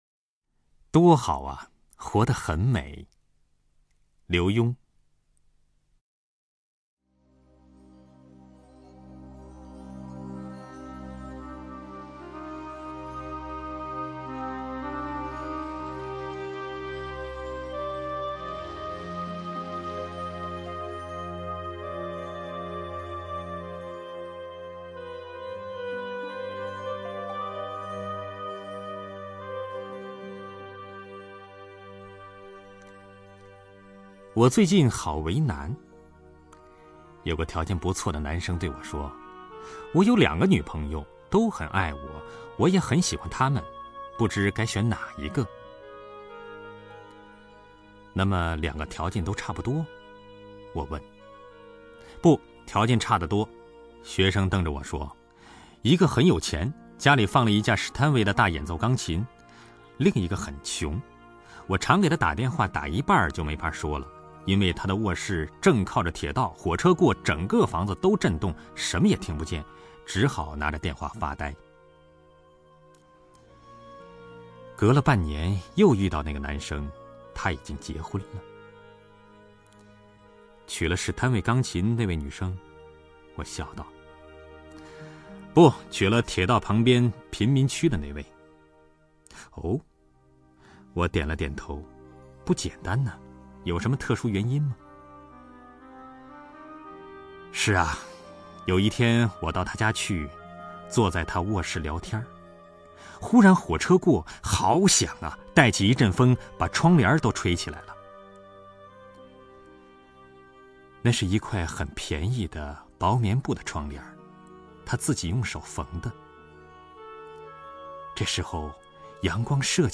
张政朗诵：《多好啊！活得很美》(刘墉)
名家朗诵欣赏 - 张政 - 张政朗诵：《多好啊！